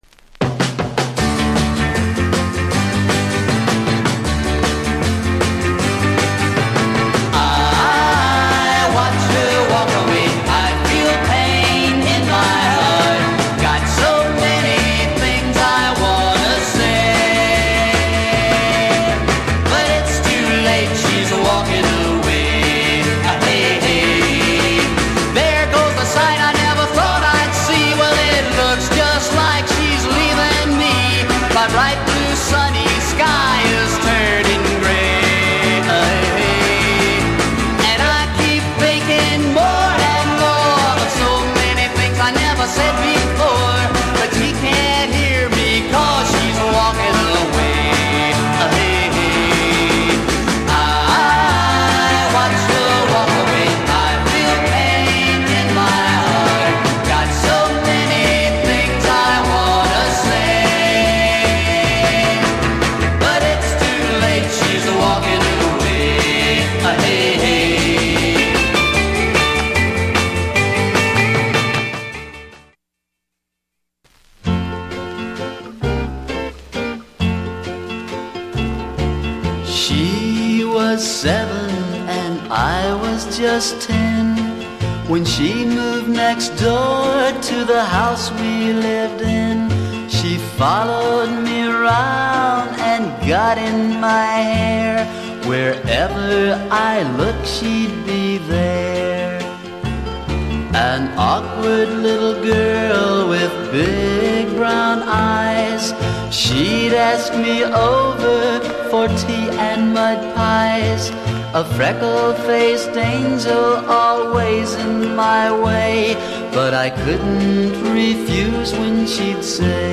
US 50's Rockabilly / R&R